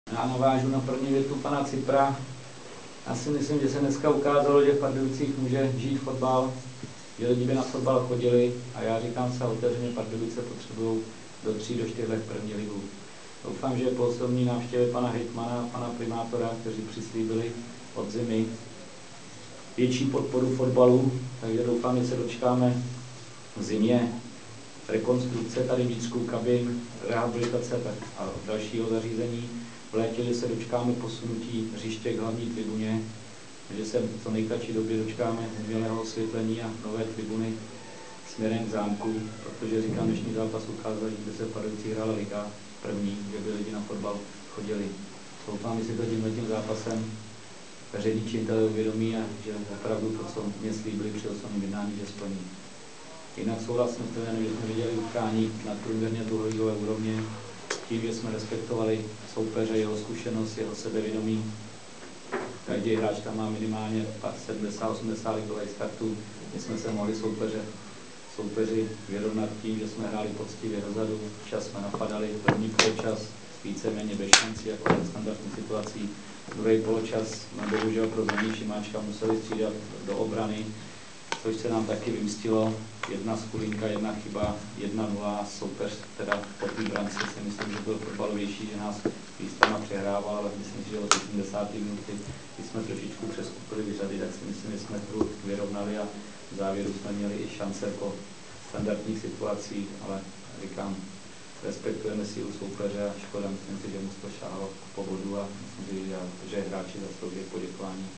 Ohlasy trenérů v MP3: